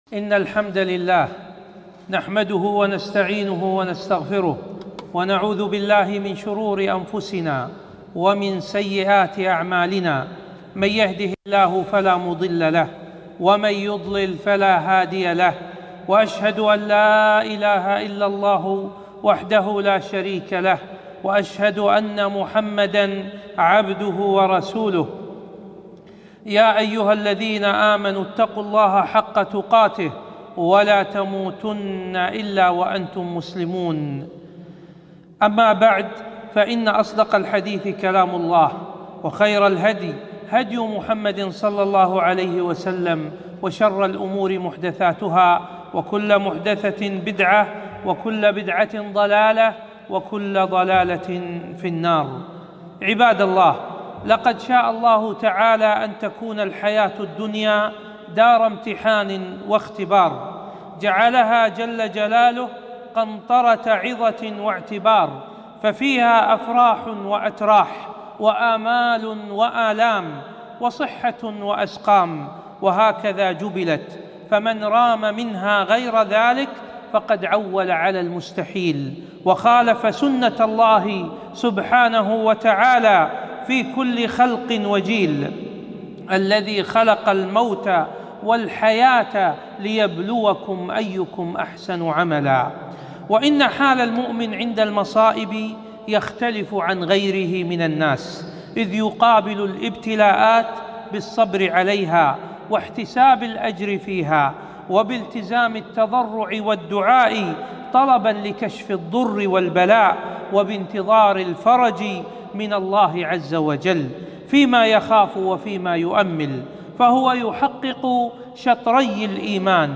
خطبة - التوكل على الله و العمل بالأسباب في كورونا